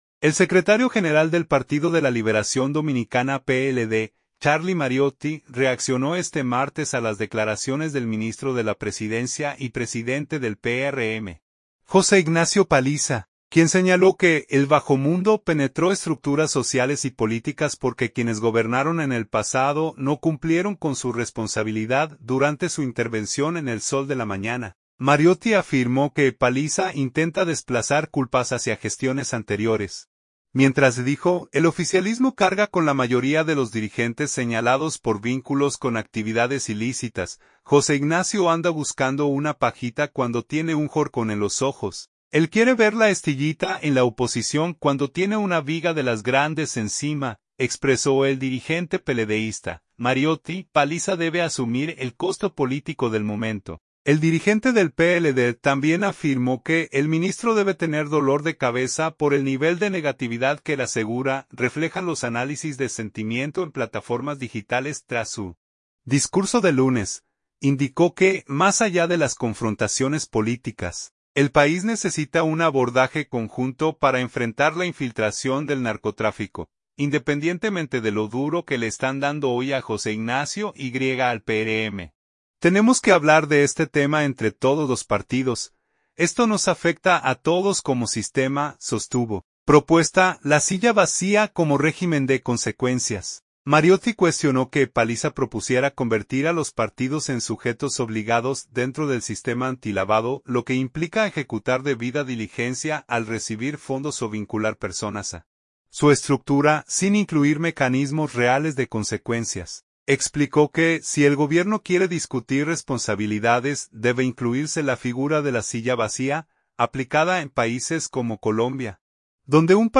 Durante su intervención en El Sol de la Mañana, Mariotti afirmó que Paliza intenta desplazar culpas hacia gestiones anteriores, mientras —dijo— el oficialismo carga con la mayoría de los dirigentes señalados por vínculos con actividades ilícitas.
Al analizar el discurso de Paliza, Mariotti y otros comentaristas del panel advirtieron que convertir el narcotráfico en un tema de disputa electoral solo beneficia a las organizaciones criminales.